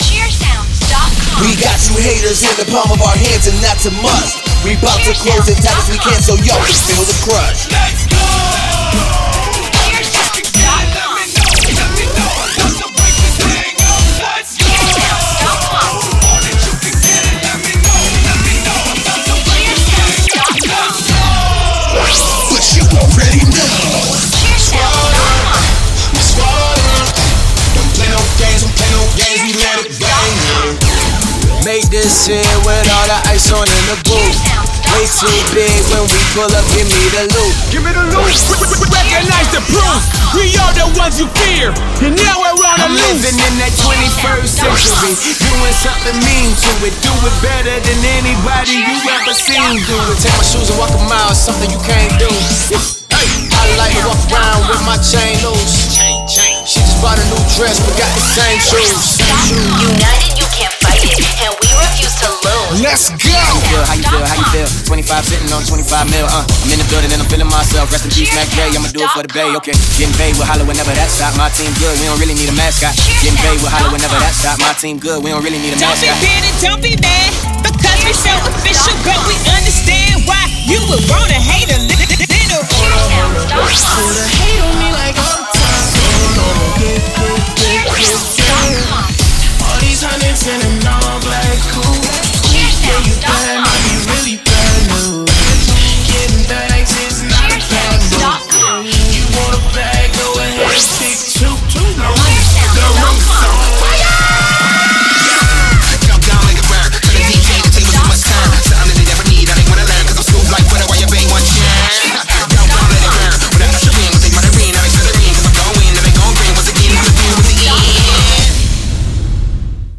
Premade Cheer Music Mix